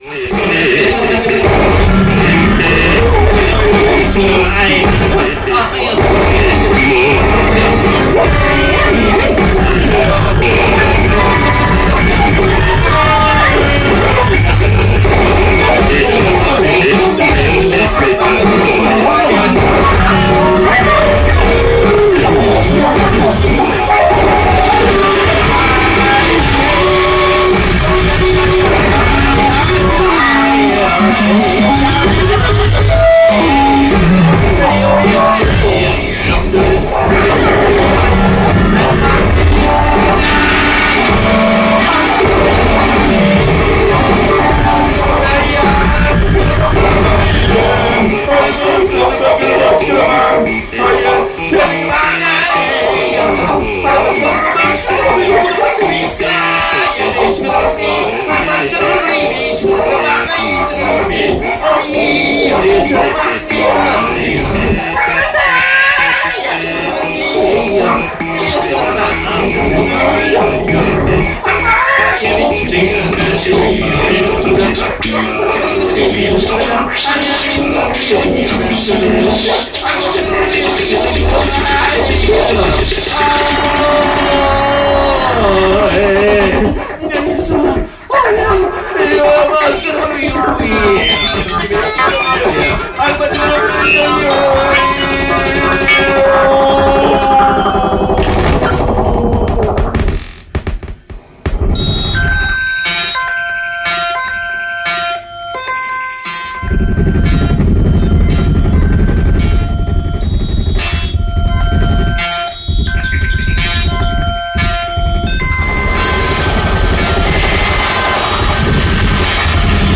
そこには、全く新しい音のバランスが現れている。
二人で演奏しているのに手が四本ある個人が演奏しているようにも錯覚してしまう。